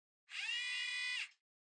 Звуки зума
10. Zoom камеры Fuji